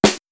DrSnare20.wav